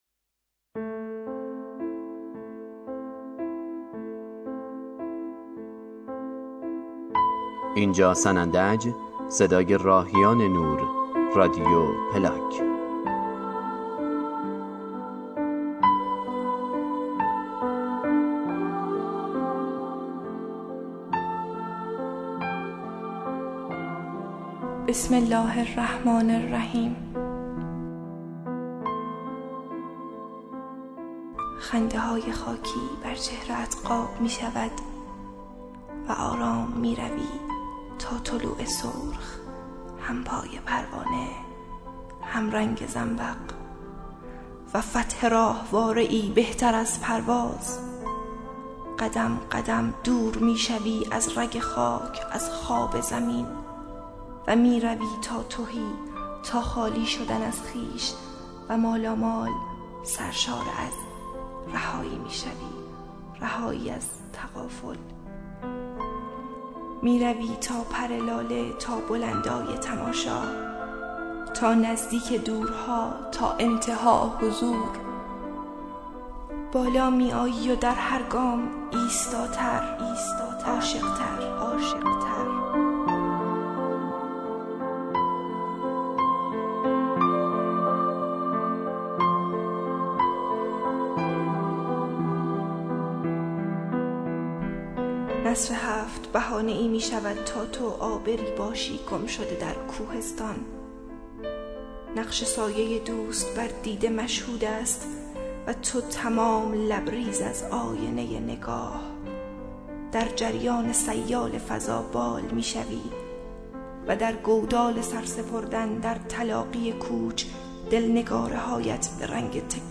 دکلمه بوسه بر مین، تقدیم به شهید محسن دین شعاری